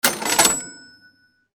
Cash Register Sha Ching Sound Effect
Description: Cash register sha ching sound effect. Deliver the iconic old school metal cash register tone.
Cash-register-cha-ching-sound-effect.mp3